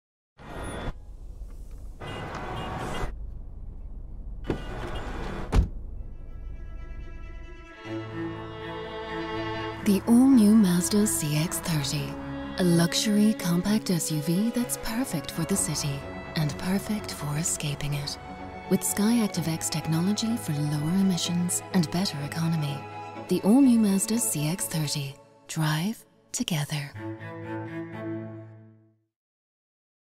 Inglês (irlandês)
Demonstração Comercial
Rode NT2 + Kaotica Eyeball